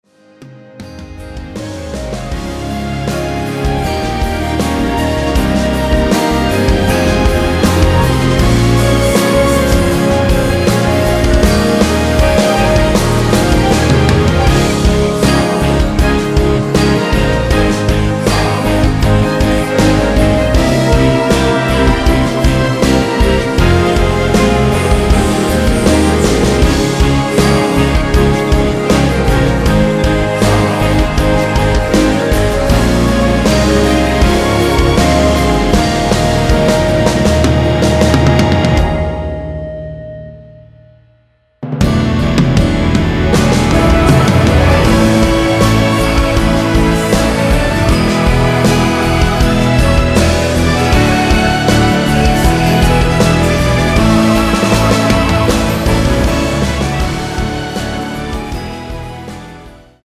원키에서(-3)내린 멜로디와 코러스 포함된 MR입니다.
Ab
앞부분30초, 뒷부분30초씩 편집해서 올려 드리고 있습니다.